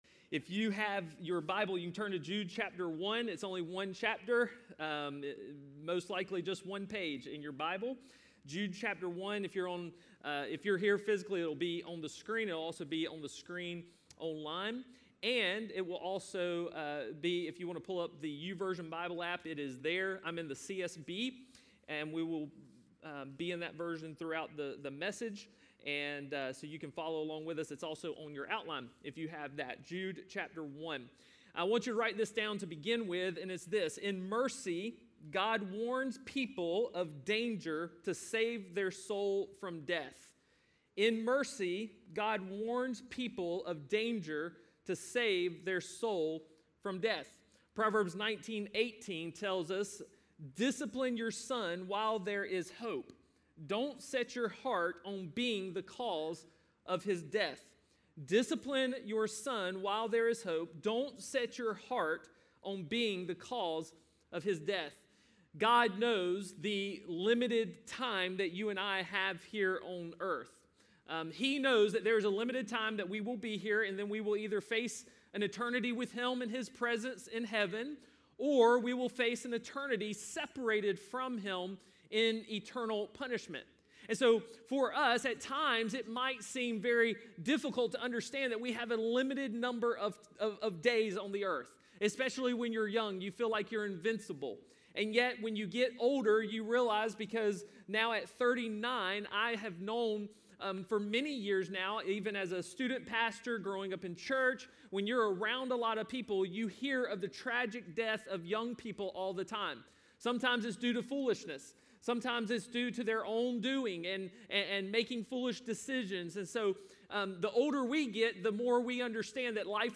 A message from the series "Bold."